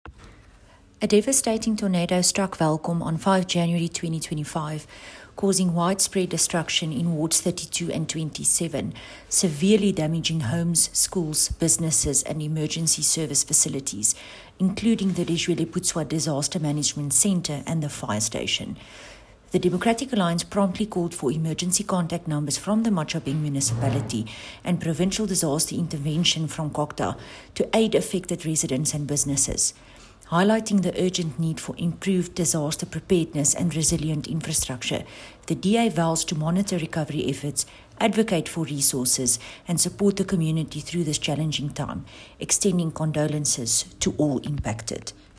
Afrikaans Soundbites by Cllr René Steyn with pictures here, here, here, and here